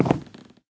wood5.ogg